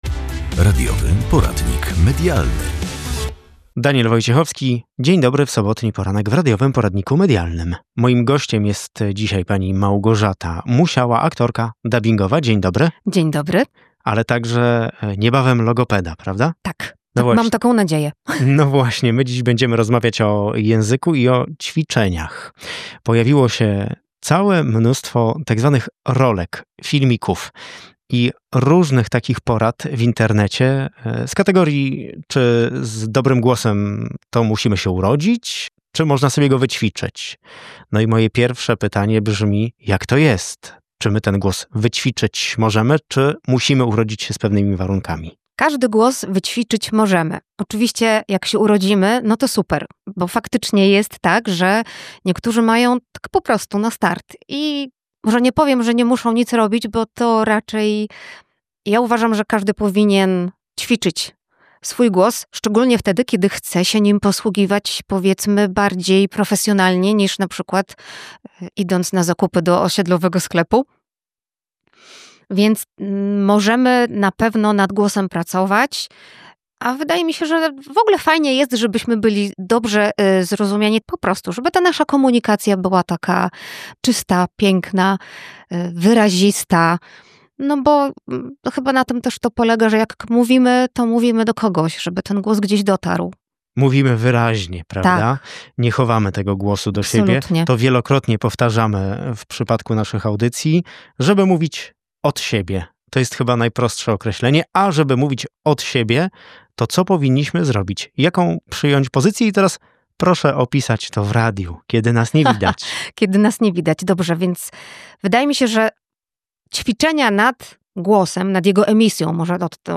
Dobry głos to talent od urodzenia, a może kwestia wyćwiczenia? Posłuchaj rozmowy z aktorką dubbingową